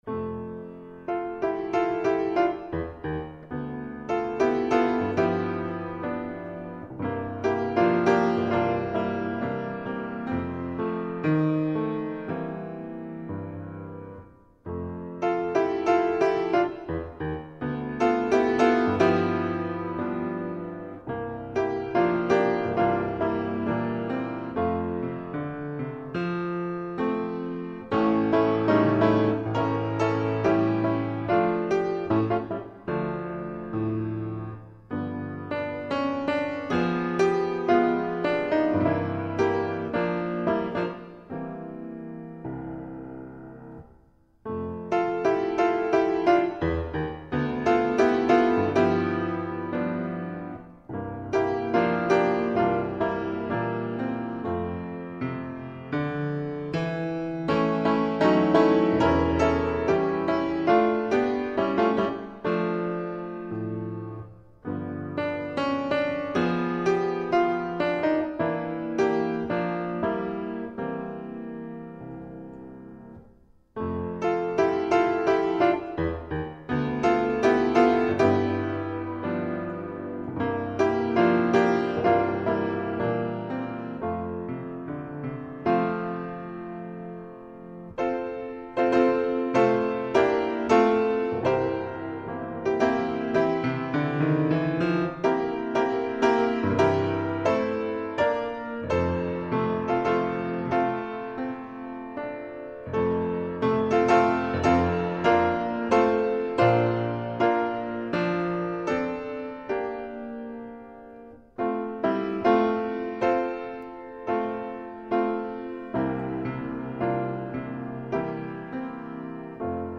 PIANO SOLO
original medley arrangement of three gospel songs